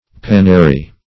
pannary - definition of pannary - synonyms, pronunciation, spelling from Free Dictionary Search Result for " pannary" : The Collaborative International Dictionary of English v.0.48: Pannary \Pan"na*ry\, a. See Panary .